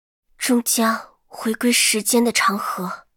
尘白禁区_安卡希雅语音_失败.mp3